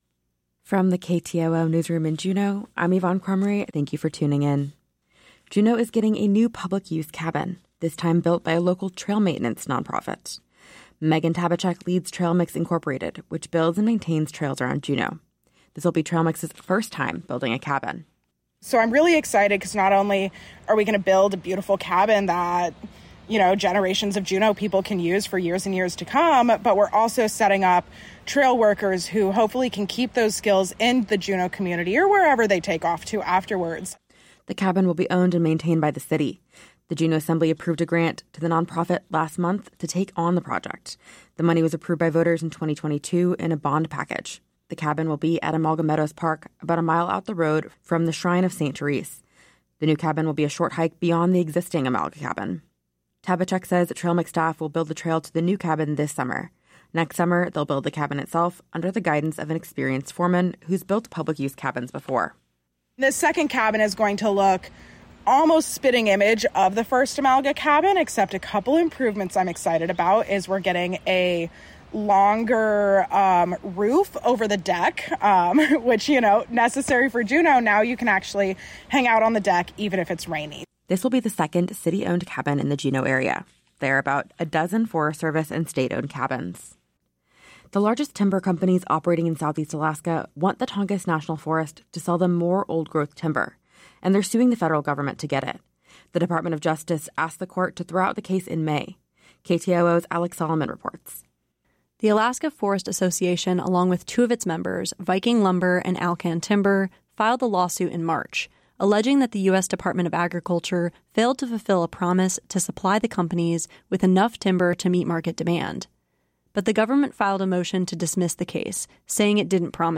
Newscast – Friday, June 6, 2025 - Areyoupop